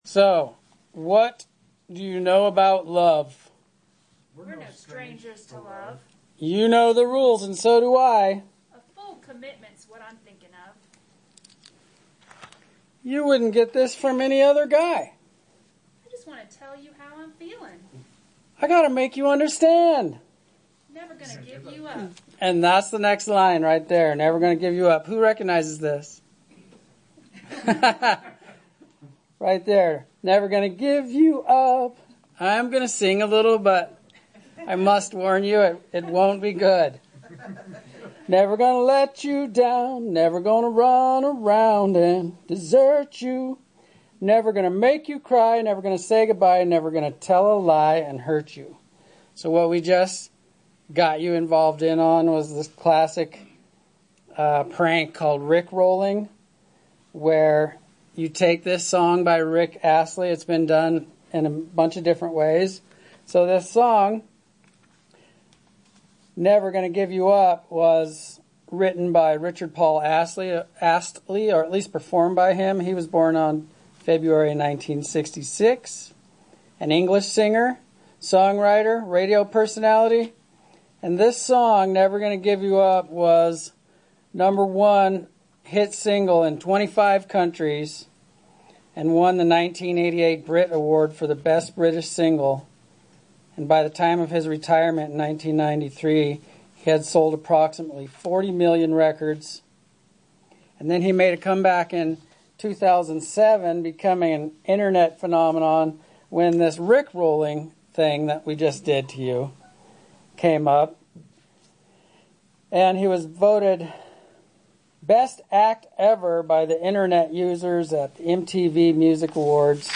Sermons
Given in Central Oregon Medford, OR